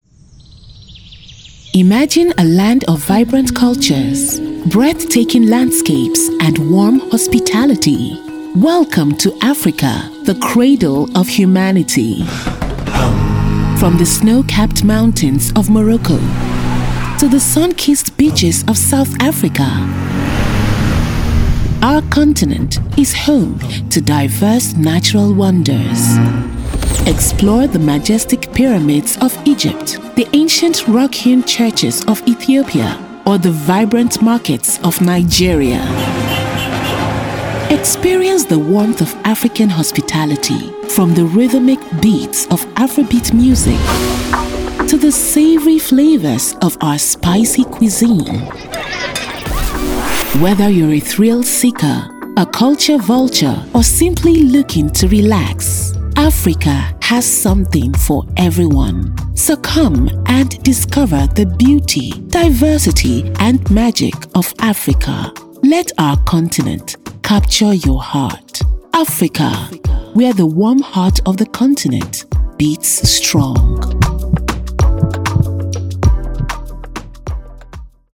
Englisch (afrikanisch)
Sprudelnd
Konversation
Befehlend